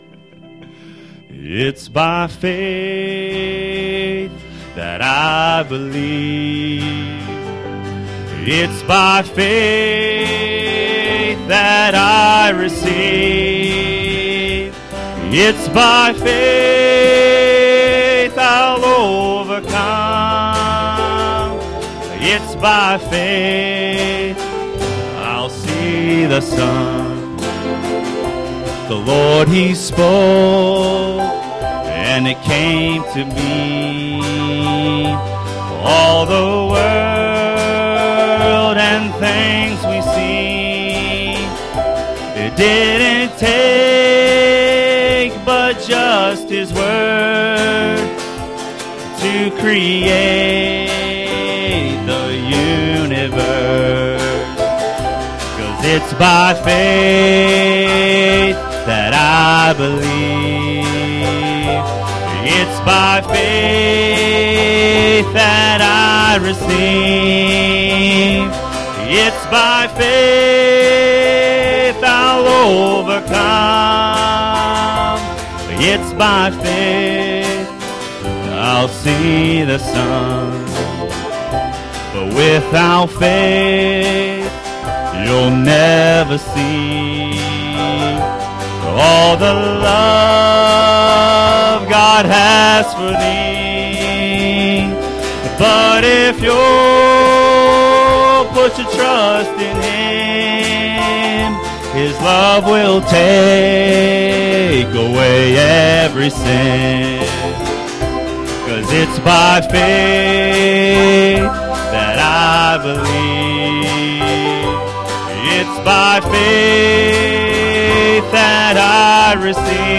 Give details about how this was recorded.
Passage: Ezekiel 18:20 Service Type: Sunday Morning